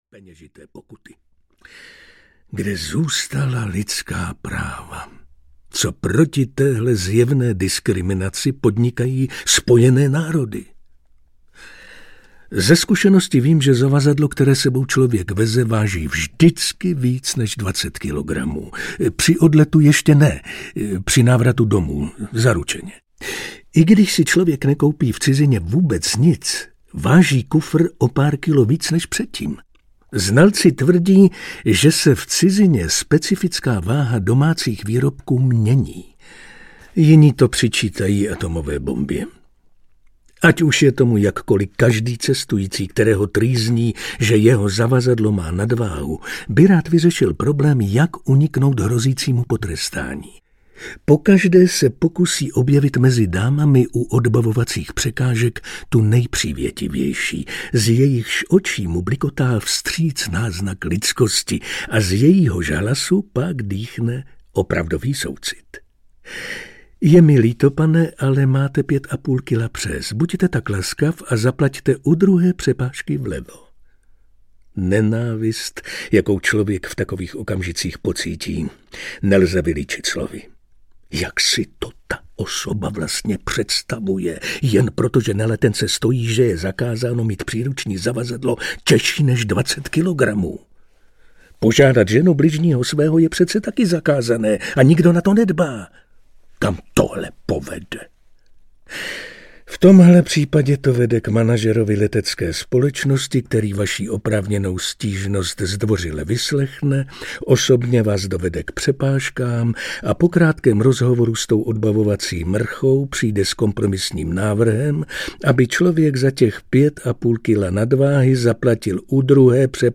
Nejlepší povídky z cest audiokniha
Ukázka z knihy
• InterpretViktor Preiss